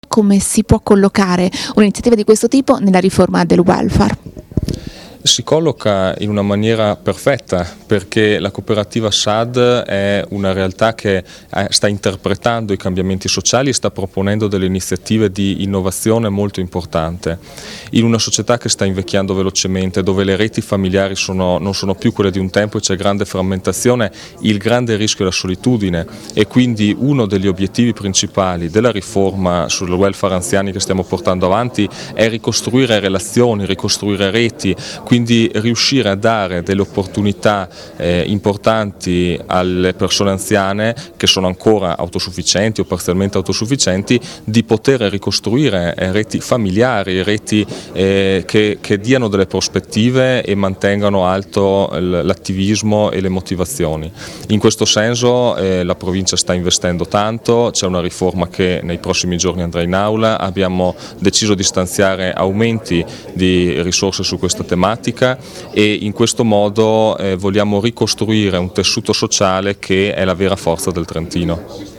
Intervista_Zeni_Casa_Cles_(1).mp3